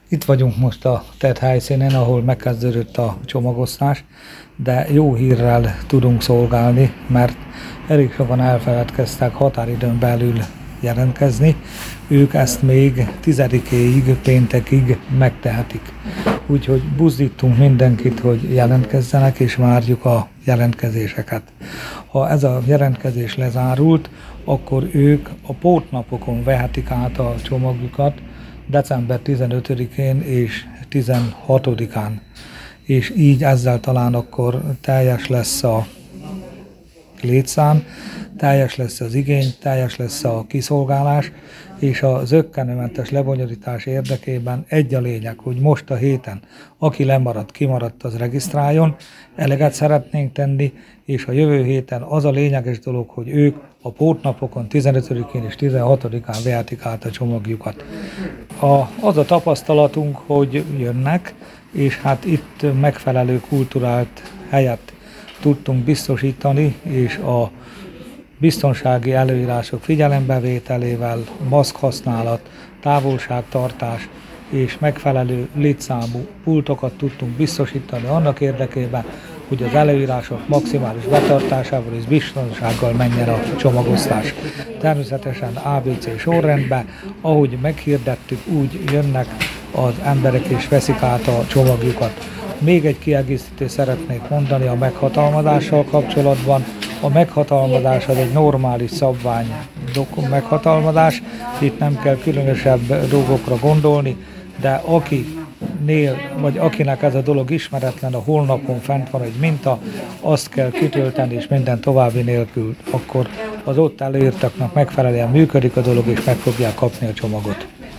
December 10-re módosult a karácsonyi csomagra való regisztráció határideje Szekszárdon. Az osztás helyszínén tartott sajtótájékoztatón Gyurkovics János alpolgármester arra is felhívta a figyelmet, hogy ha más nevében vinnénk el...